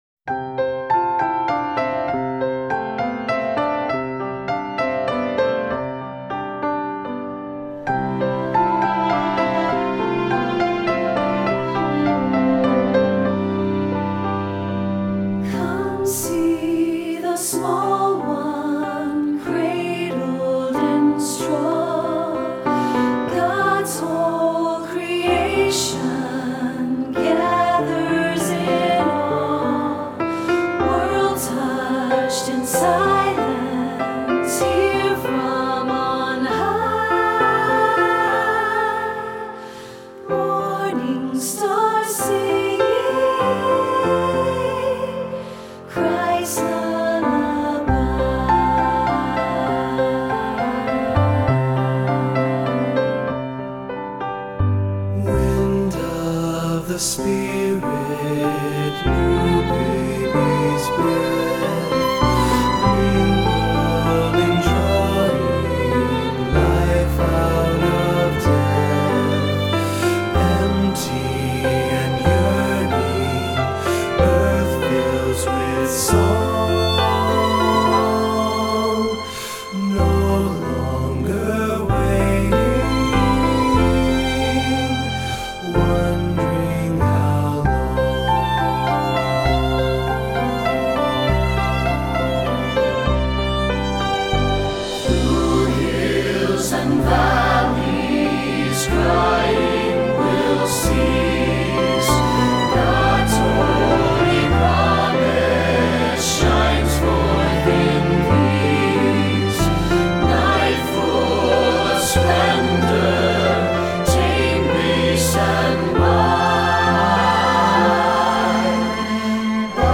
Voicing: Assembly,SATB